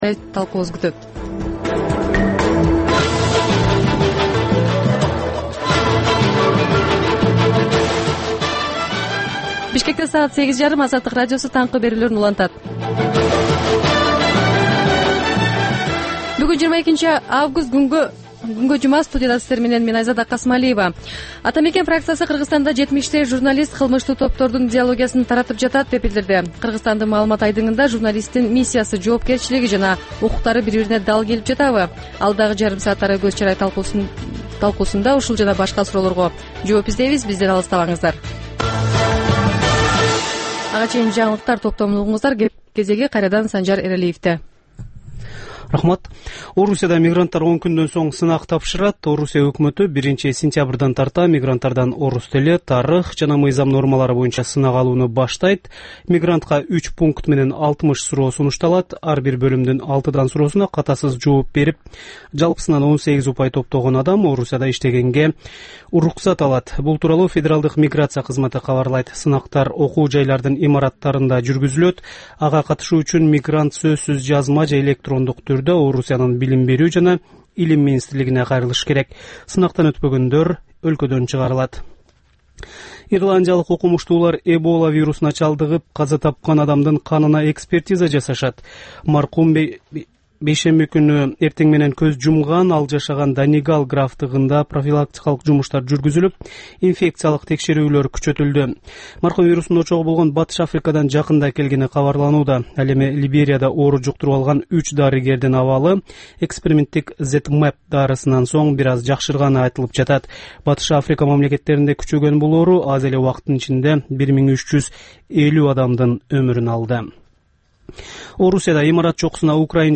Бул таңкы үналгы берүүнүн 30 мүнөттүк кайталоосу жергиликтүү жана эл аралык кабарлар, ар кыл орчун окуялар тууралуу репортаж, маек, күндөлүк басма сөзгө баяндама, «Арай көз чарай» түрмөгүнүн алкагындагы тегерек үстөл баарлашуусу, талкуу, аналитикалык баян, сереп жана башка берүүлөрдөн турат. "Азаттык үналгысынын" бул берүүсү Бишкек убакыты боюнча саат 08:30дан 09:00га чейин обого чыгарылат.